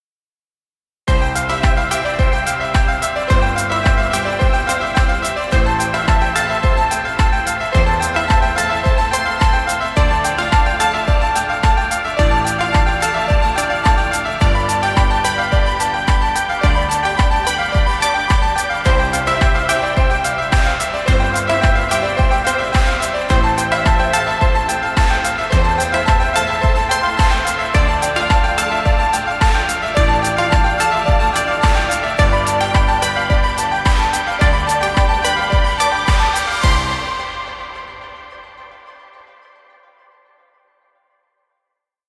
positive corporate motivational music.
Corporate music for video.